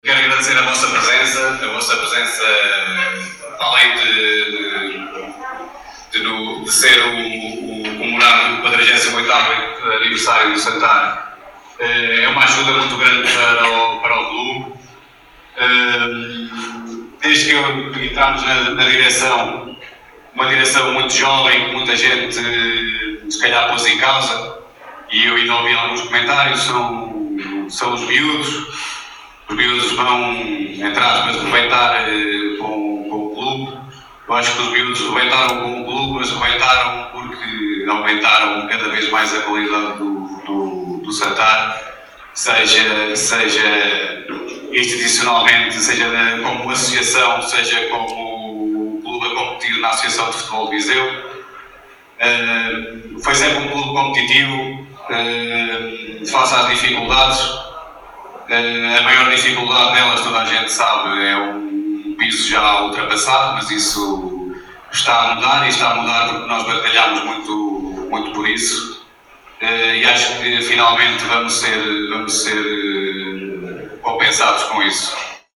Este domingo, 27 de julho, realizou-se na sede do Sporting Clube de Santar o almoço do 48º aniversário, onde reuniu Associados, Dirigentes, Representante da Associação de Futebol de Viseu, Presidente da União de Freguesias de Santar e Moreira e Presidente da Câmara Municipal de Nelas.